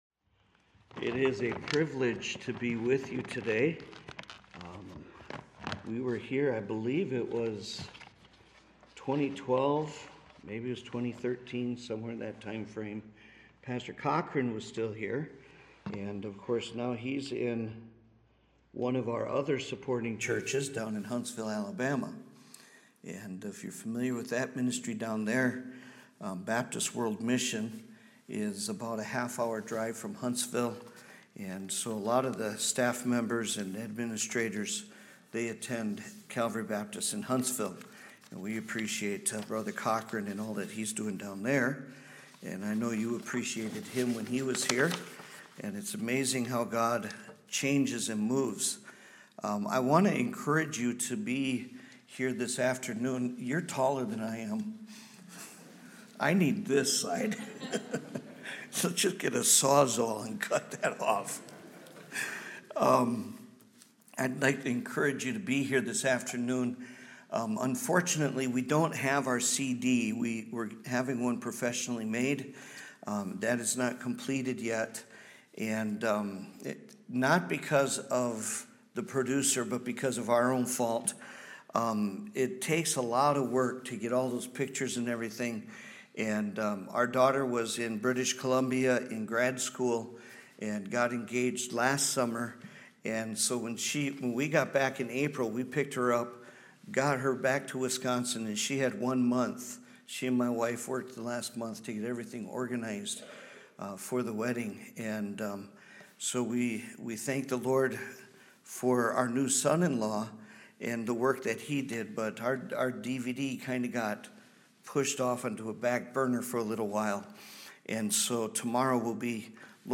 Missionary Passage: 2 Corinthians 5 Service Type: Sunday Morning Service A message from one of our missionaries